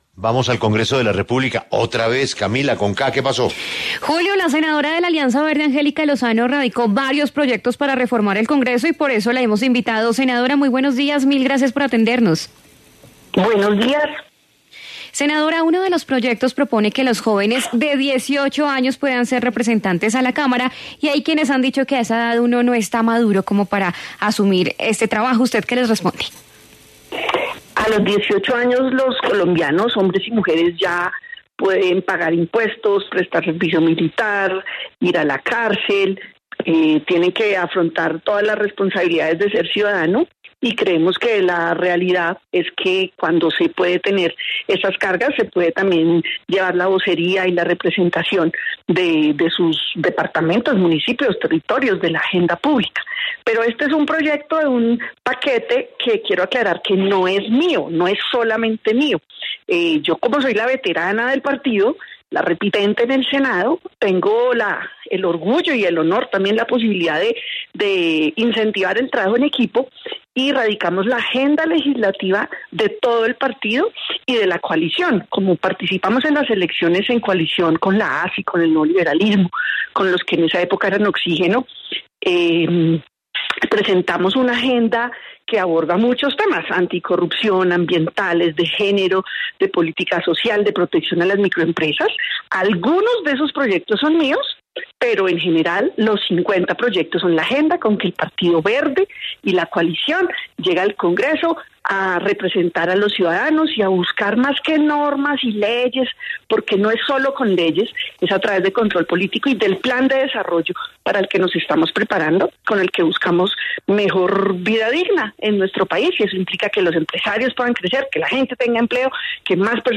En La W, la senadora Angélica Lozano defendió la iniciativa tras las críticas.
En diálogo con La W, la senadora de la Alianza Verde Centro Esperanza, Angélica Lozano, habló de los proyectos que radicó su coalición para reformar el Congreso.